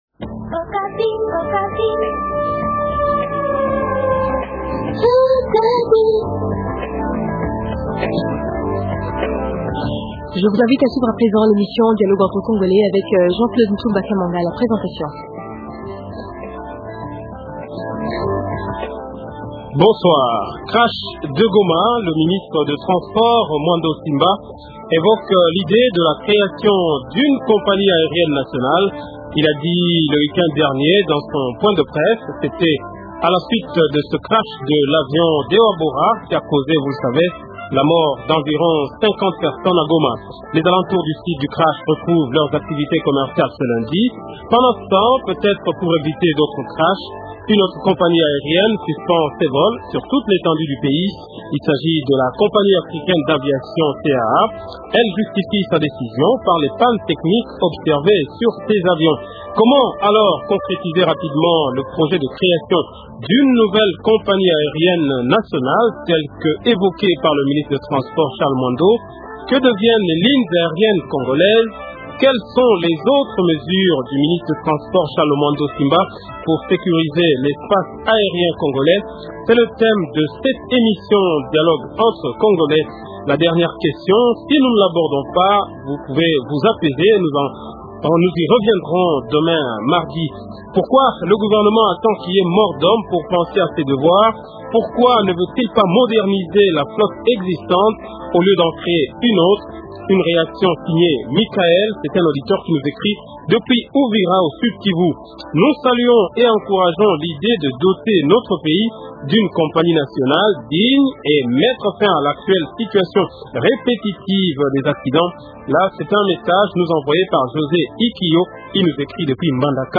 C’était au cours d’un point de presse animé le vendredi 18 Avril 2008 dernier en son cabinet de travail à Kinshasa.